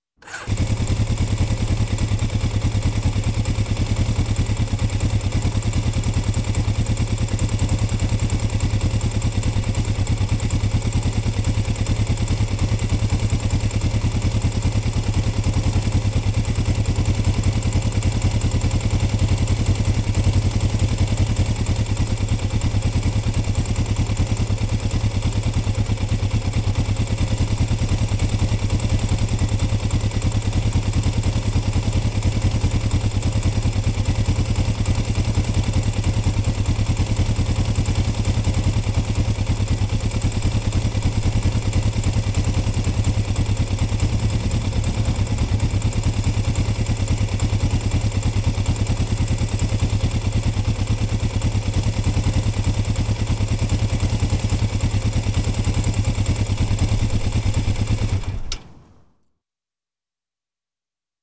Звуки мопеда, скутера
Звук мотоцикла на звонок